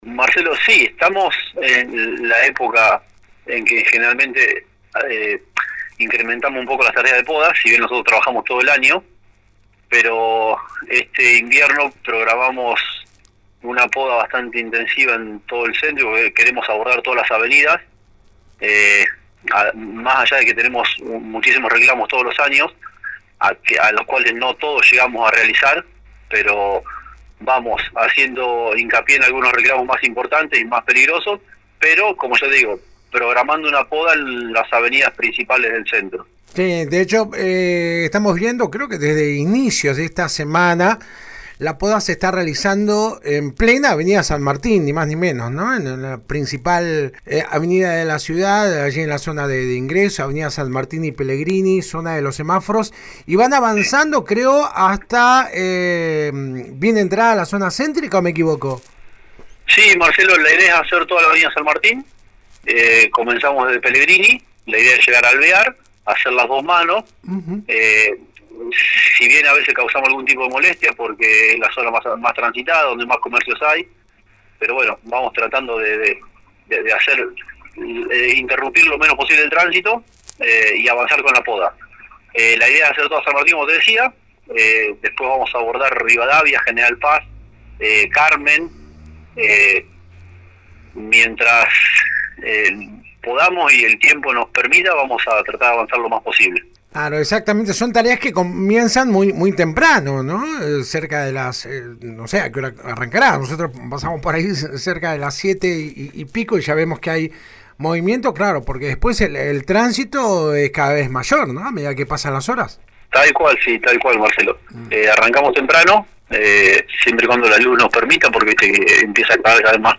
El director de plazas, parques, paseos y forestación municipal habló este jueves en la 91.5 sobre los trabajos actuales de poda en la ciudad.